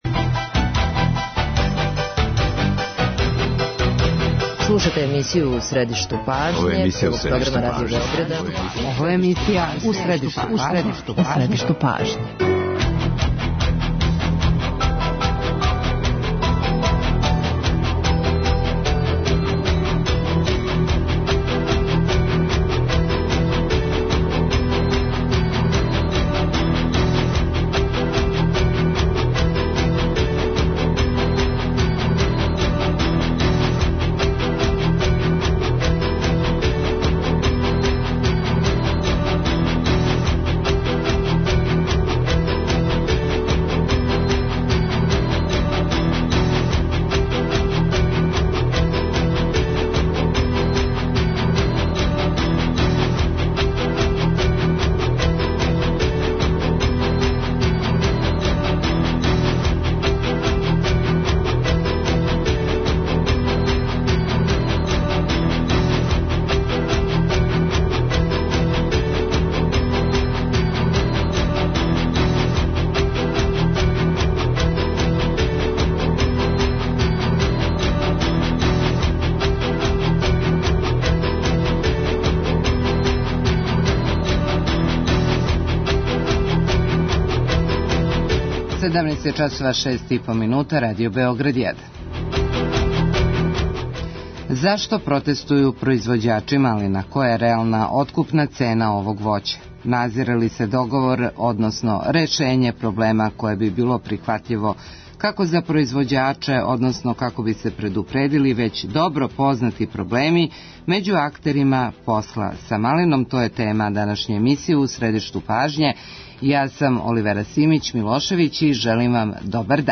Гости у студију су